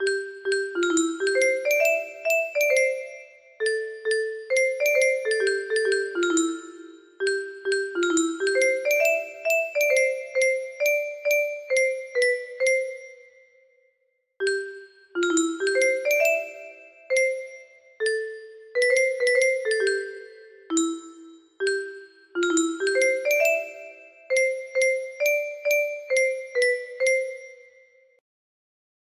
John Brown Song (correct) music box melody